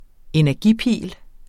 Udtale [ enæɐ̯ˈgi- ]